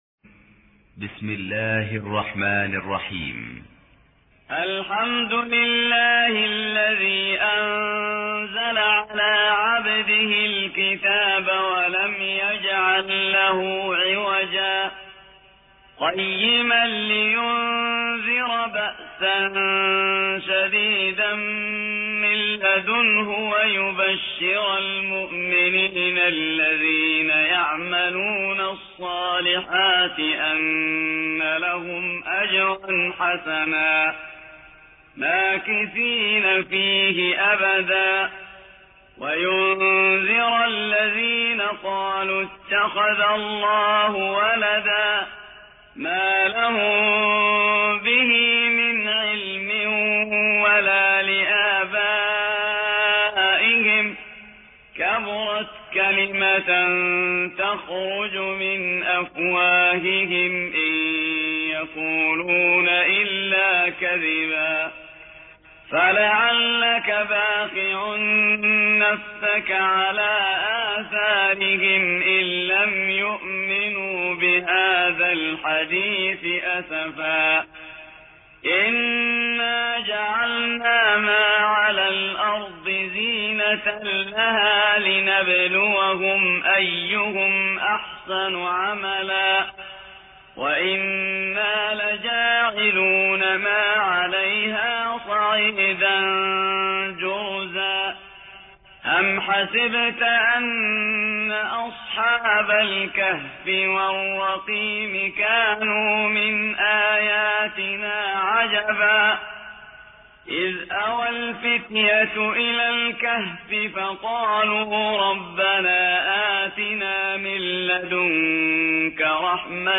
18. سورة الكهف / القارئ